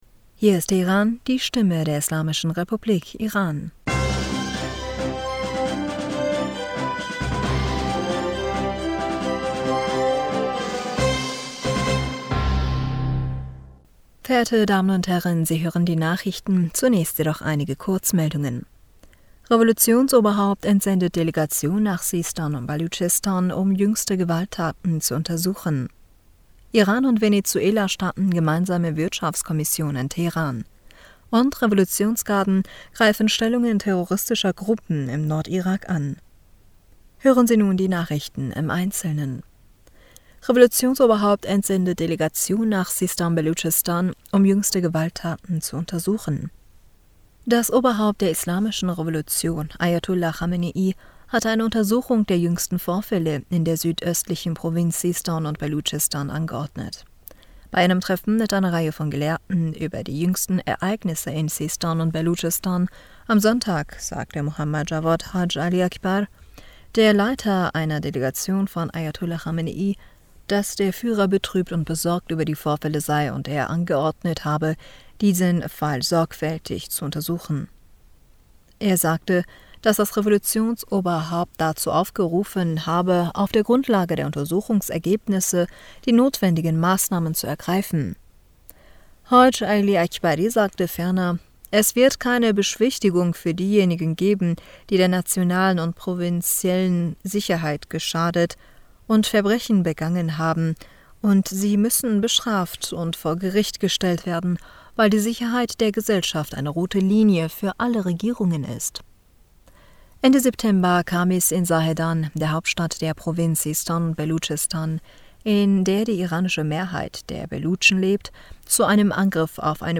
Nachrichten vom 14. November 2022